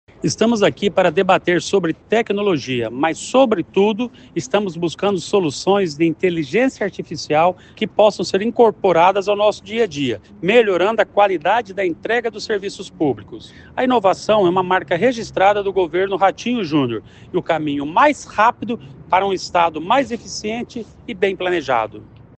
Sonora do secretário do Planejamento, Ulisses Maia, sobre a participação do Estado no Google Public Sector Summit